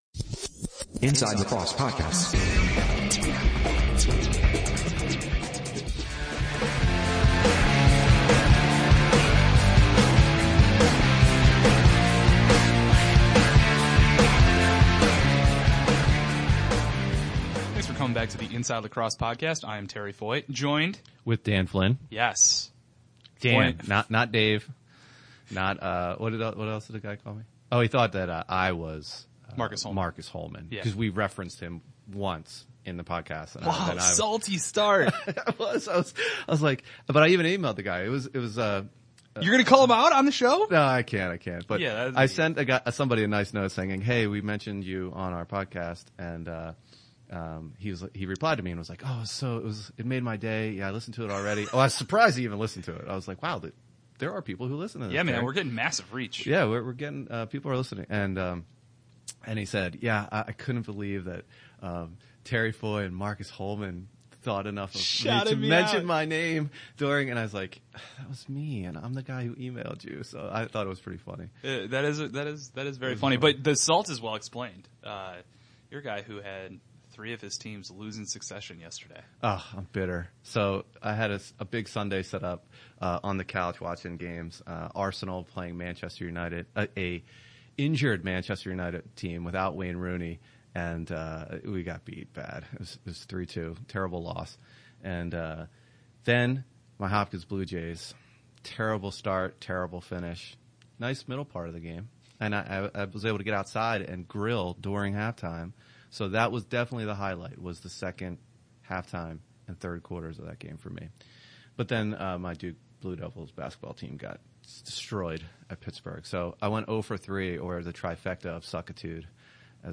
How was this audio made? break out some new and improved recording gear